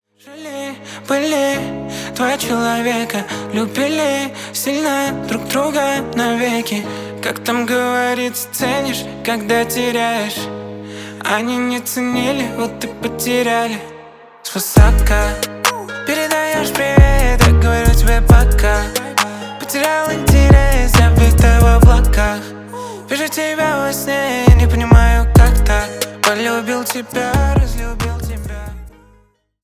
Поп Музыка
спокойные # грустные # тихие